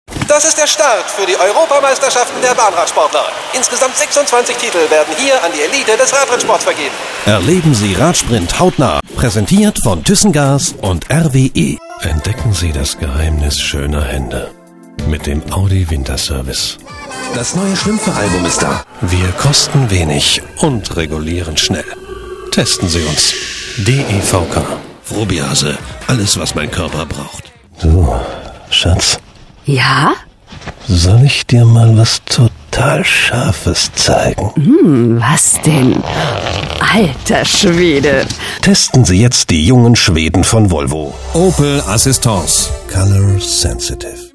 deutscher Sprecher für Nachrichten, TV-Features, Magazinbeiträge, Imagefilme, Produktfilme, Schulungsfilme, Werbespots, On-Air-Promotion, Hörspiele, Hörbücher, Synchronrollen.
Kein Dialekt
Sprechprobe: Werbung (Muttersprache):